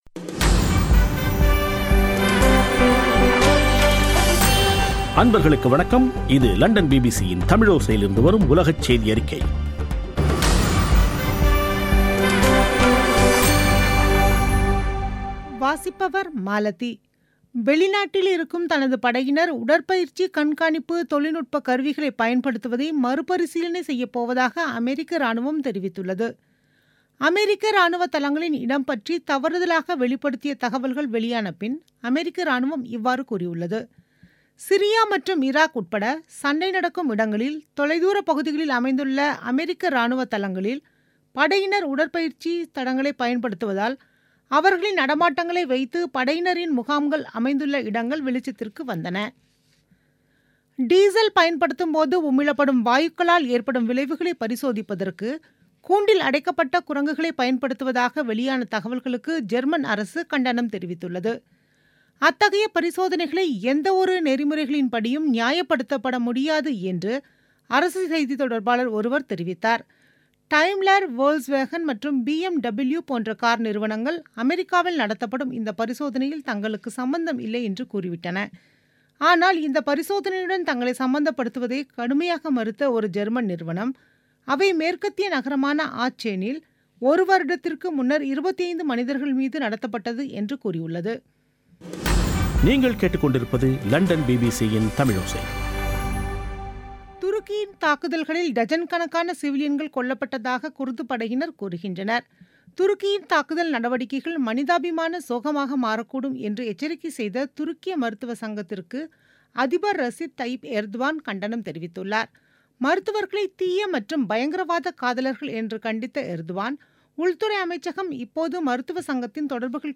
பிபிசி தமிழோசை செய்தியறிக்கை (29/01/2018)